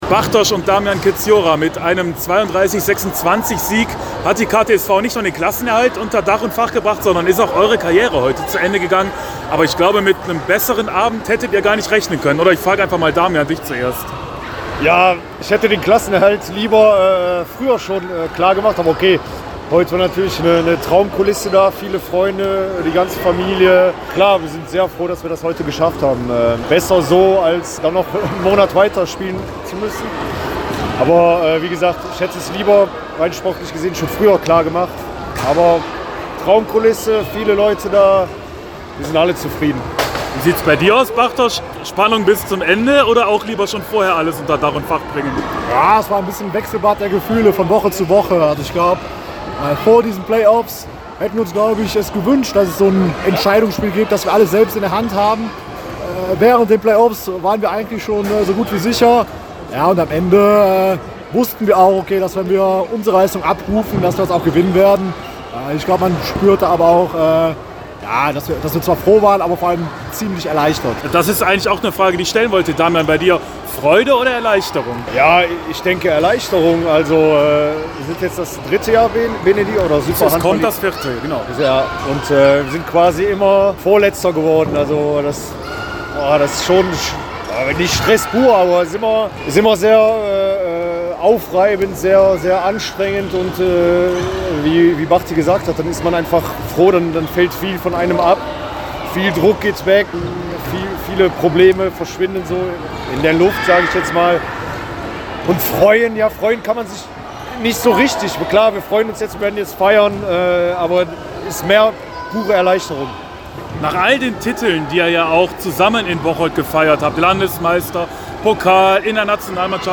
mit den beiden nach dem Spiel gesprochen